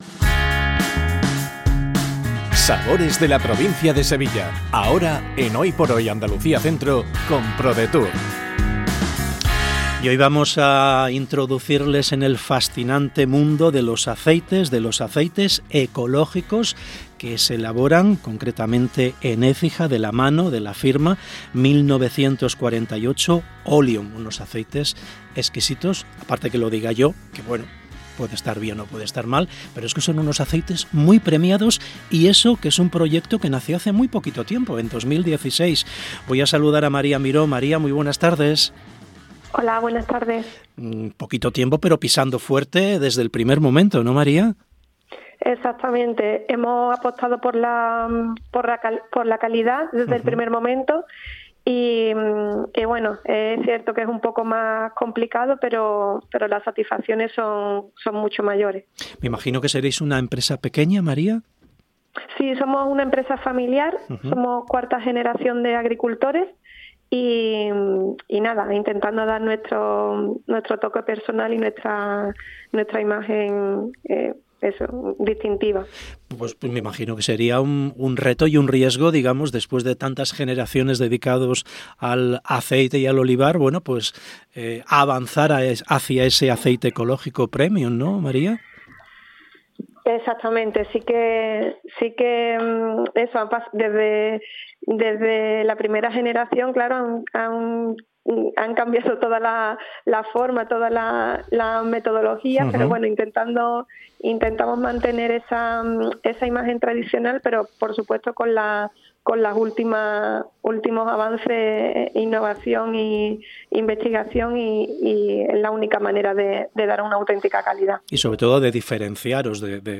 ENTREVISTA 1948 OLEUM